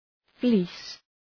Shkrimi fonetik {fli:s}